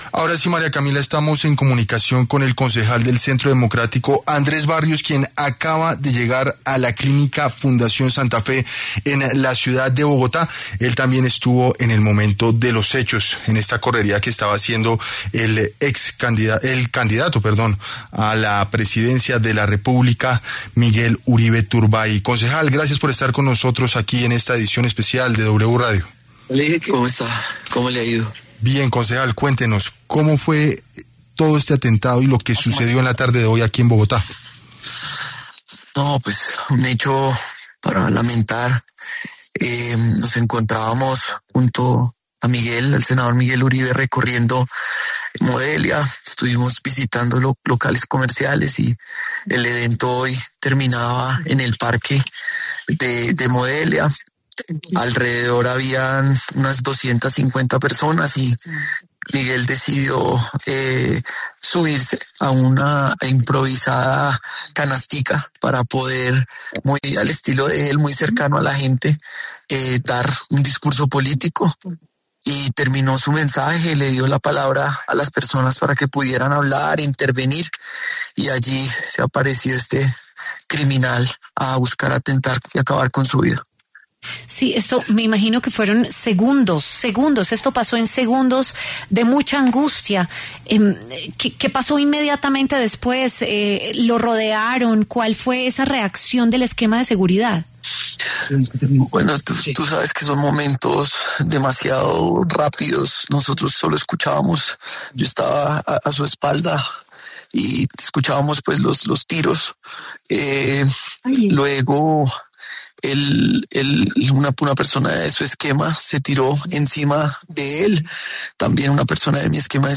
Uno de los presentes fue el concejal de Bogotá Andrés Barrios, quien compartió ante los micrófonos de W Radio cómo fueron los momentos cuando sucedió el atentado.